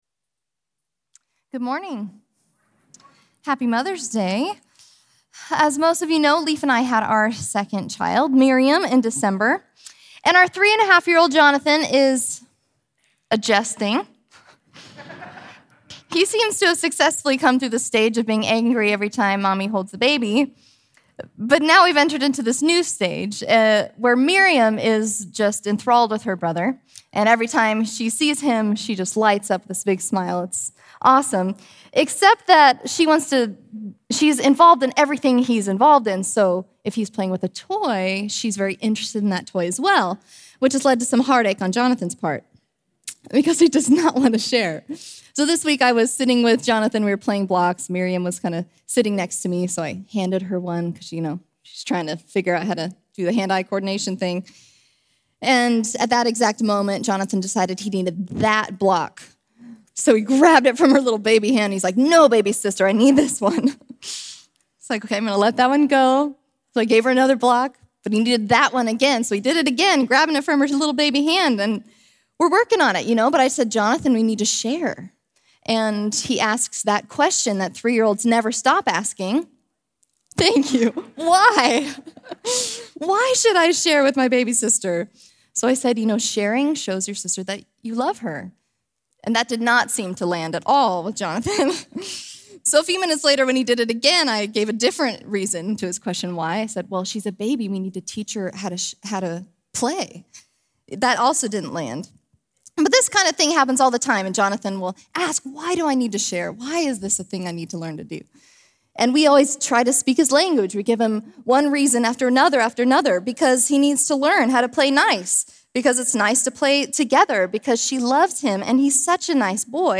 May 21, 2023 – God Does Not Change (Message Only) – Glenkirk Church